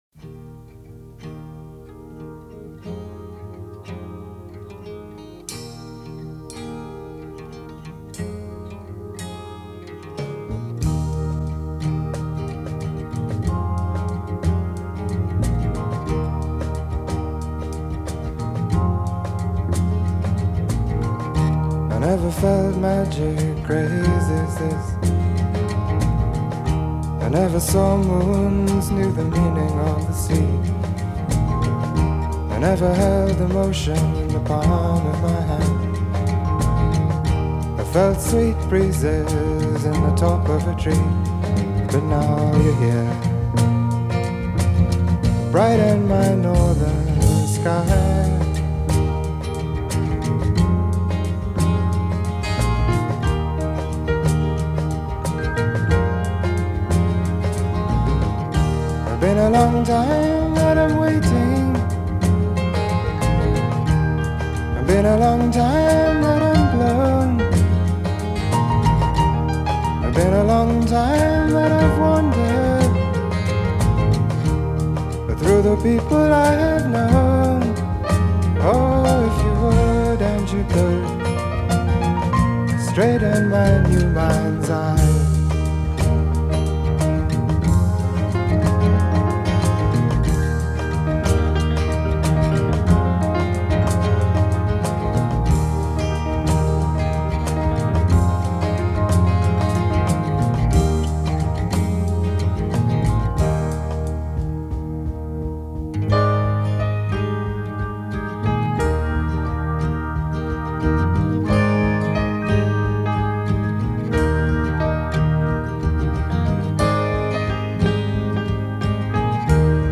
on the celesta